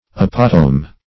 Apotome \A*pot"o*me\ ([.a]*p[o^]t"[-o]*m[-e]), n. [Gr. 'apotomh`